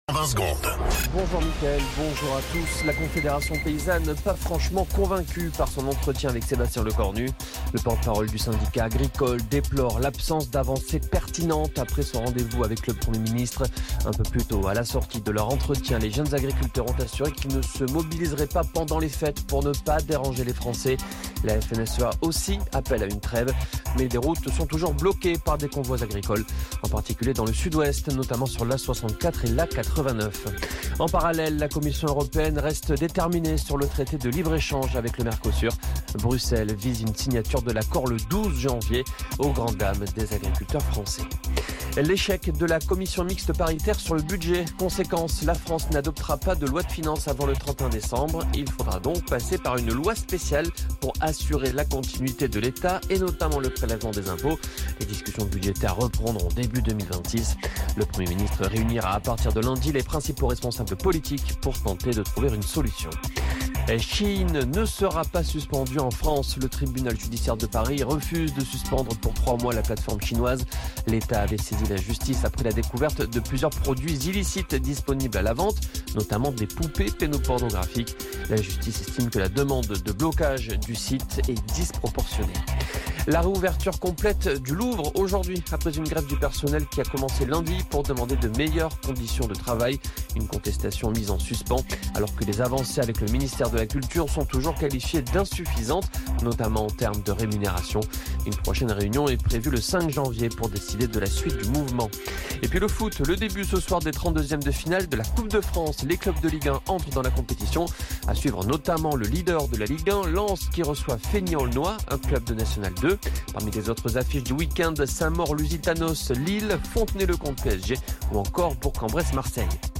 Flash Info National 19 Décembre 2025 Du 19/12/2025 à 17h10 .